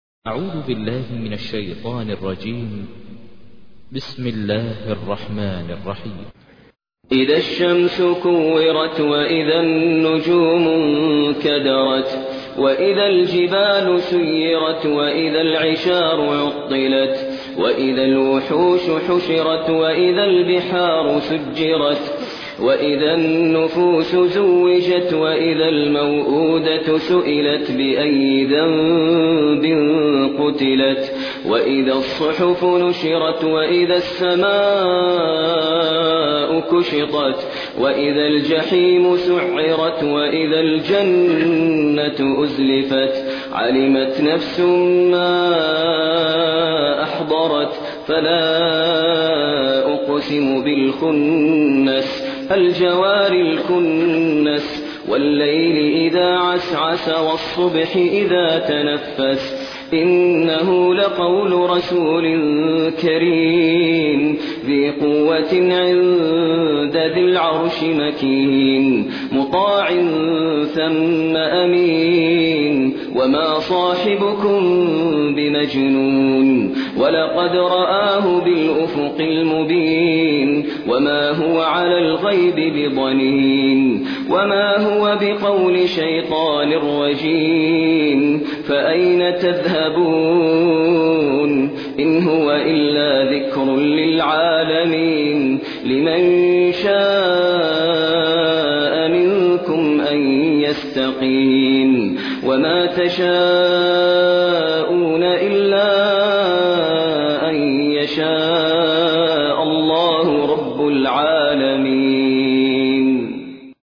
تحميل : 81. سورة التكوير / القارئ ماهر المعيقلي / القرآن الكريم / موقع يا حسين